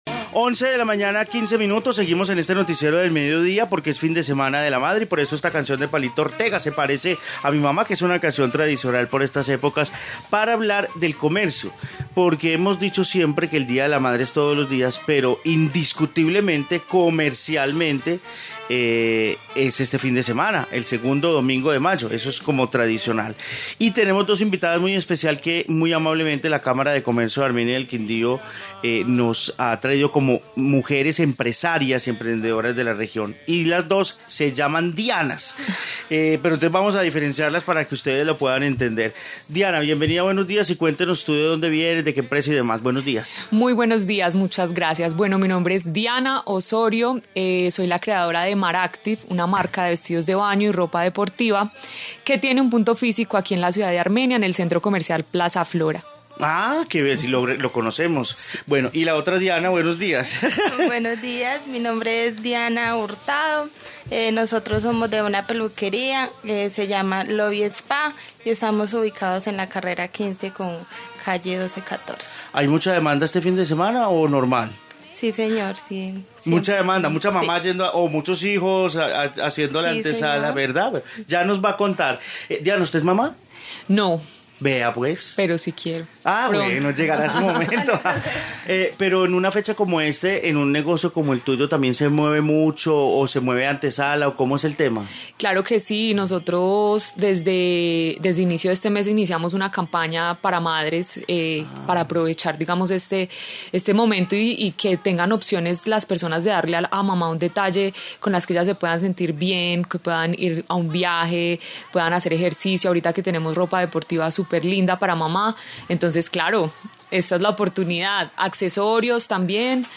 Informe comercio día de la madre